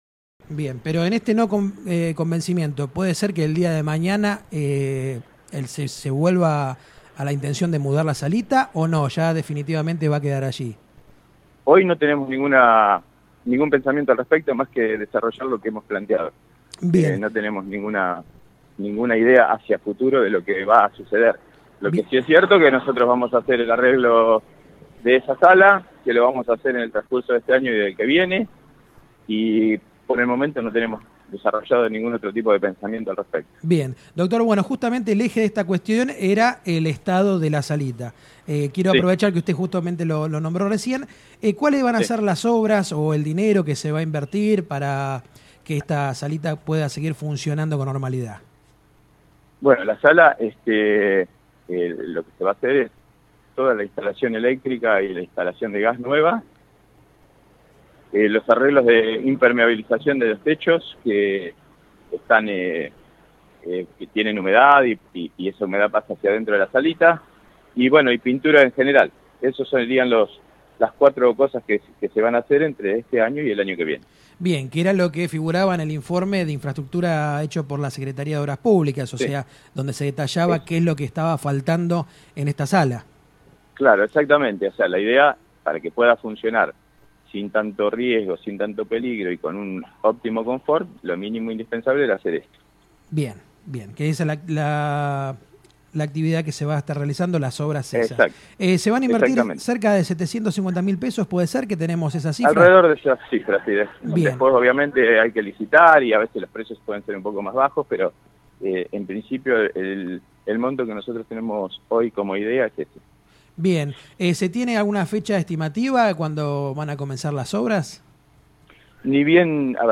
media-photo_5915db55d669e67b19cf0389_640wAsi lo expreso el Dr. Julio Adrover Secretario de Salud del municipio de Pergamino en comunicación telefónica con el programa «Sin Filtro» que se emite de martes a viernes a partir de las 10 de la mañana por «Radio Verdad» fm 99.5.